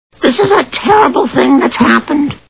The Simpsons [Marge] Cartoon TV Show Sound Bites